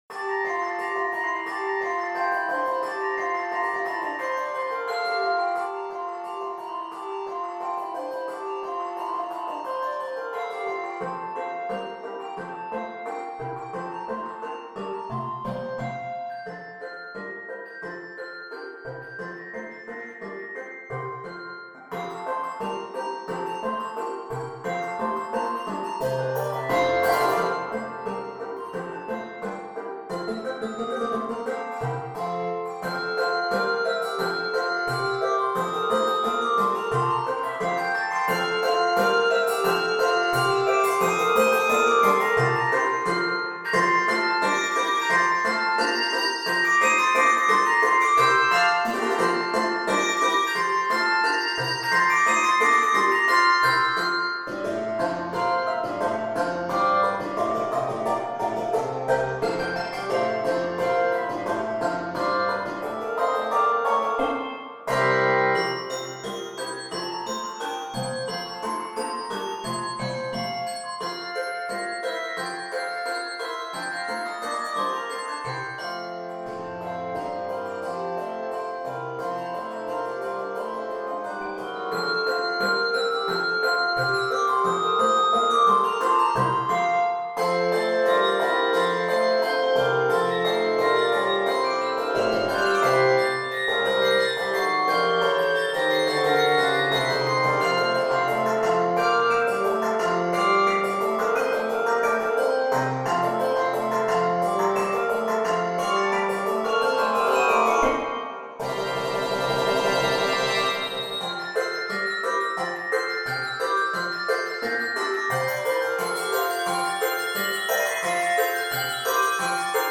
handbells
Key of F Major. 100 measures.